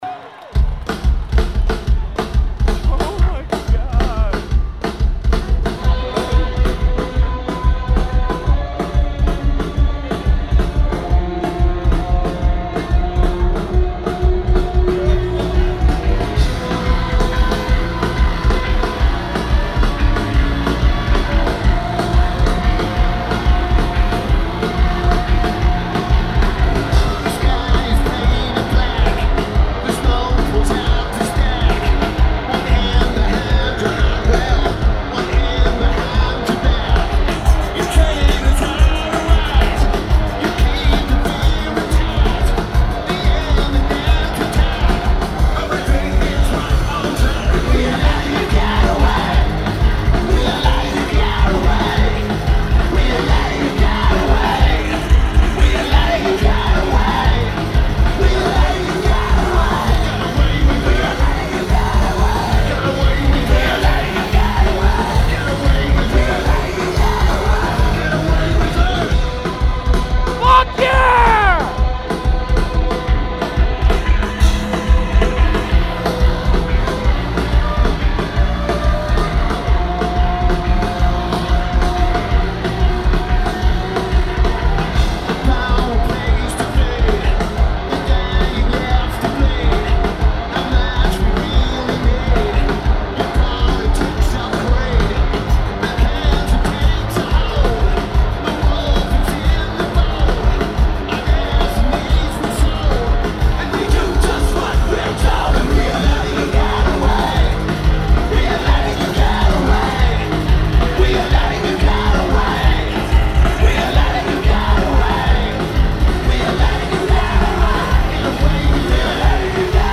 Red Rocks Amphitheatre
Drums
Guitar
Recorded FOB.